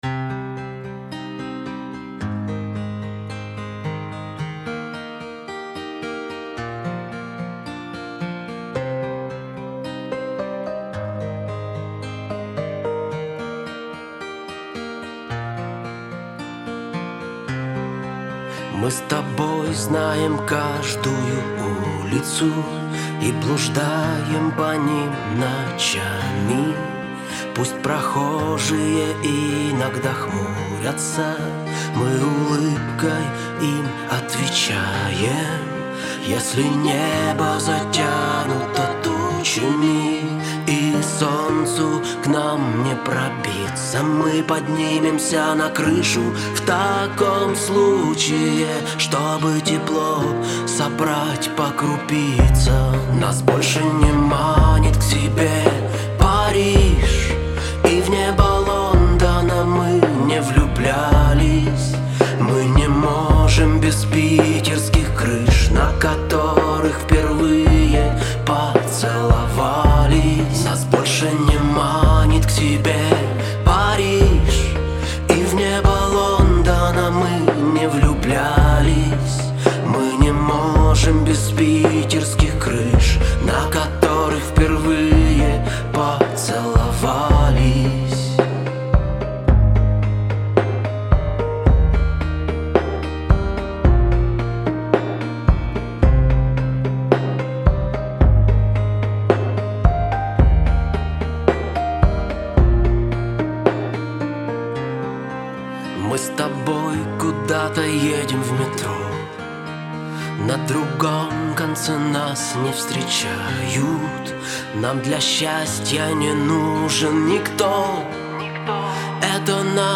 где нежные мелодии переплетаются с громогласными риффами
Вокал, гитара
Бас-гитара
Гитара
Барабаны